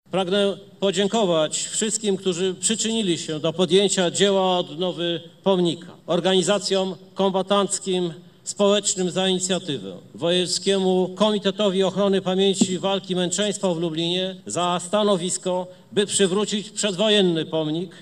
Na placu Litewskim w Lublinie uroczyście odsłonięto Pomnik Nieznanego Żołnierza. To odnowiona wersja postumentu postawionego w mieście w 1925 roku, w rocznicę bitwy pod Jastkowem.